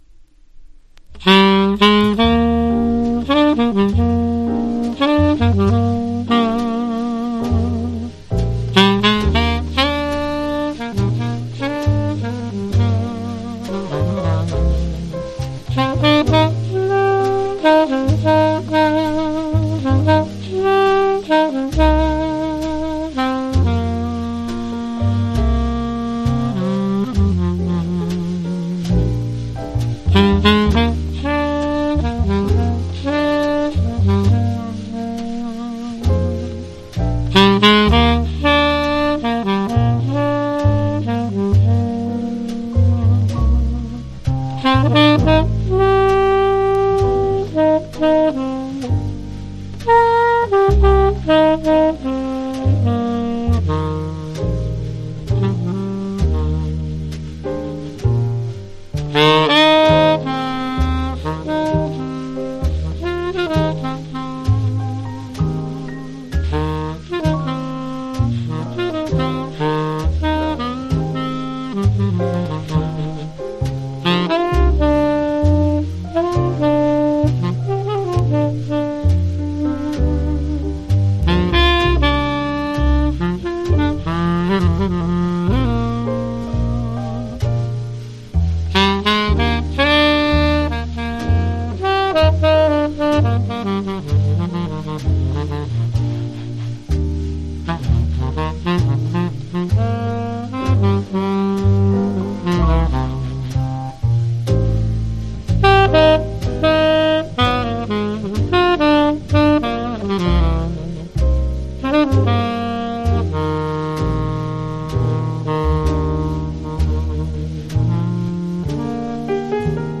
ステレオ
Genre US JAZZ